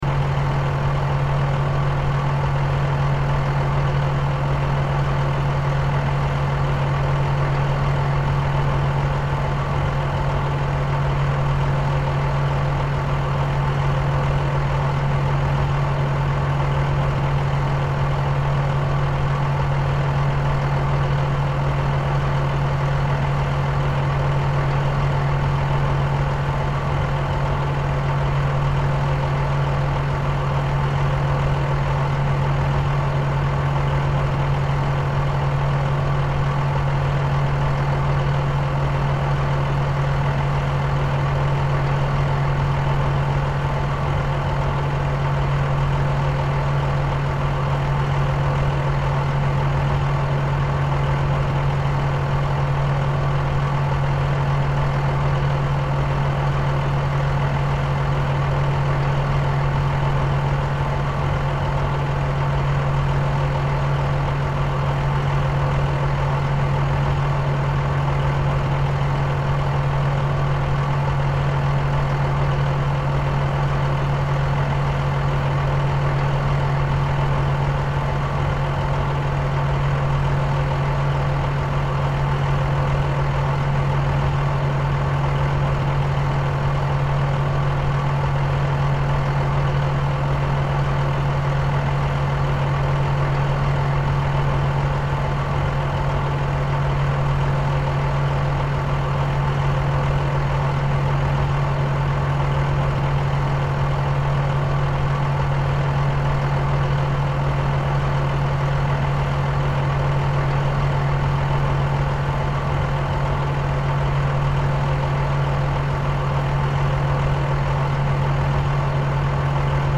Звук дизельного мотора бульдозера на холостом ходу